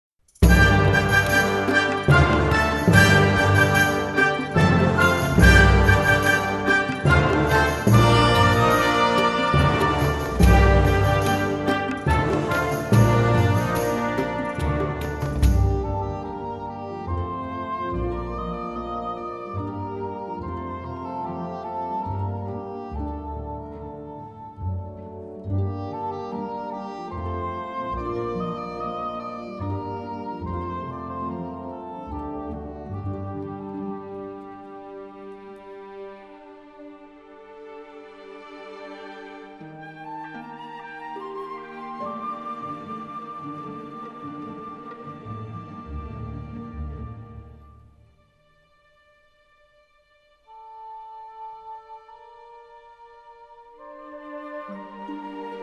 Arabic vocals and Shawm